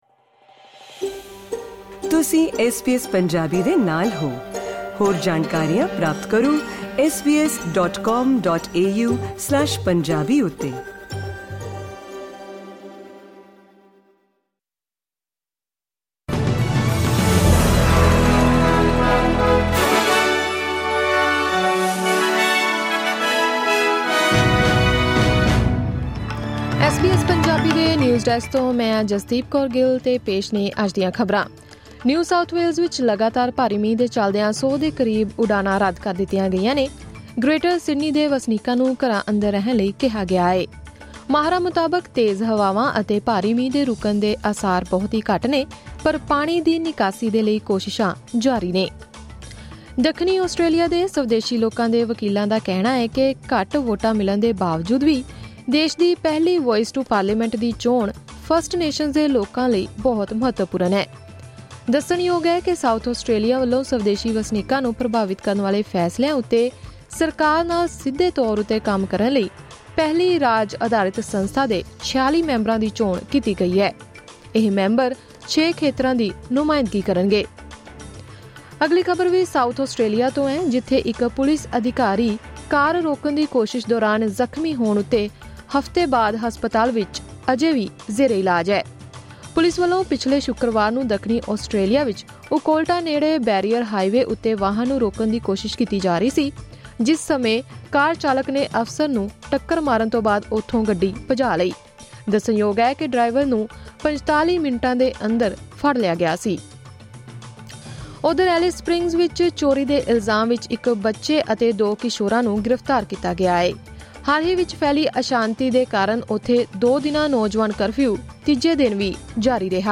ਐਸ ਬੀ ਐਸ ਪੰਜਾਬੀ ਤੋਂ ਆਸਟ੍ਰੇਲੀਆ ਦੀਆਂ ਮੁੱਖ ਖ਼ਬਰਾਂ: 5 ਅਪ੍ਰੈਲ, 2024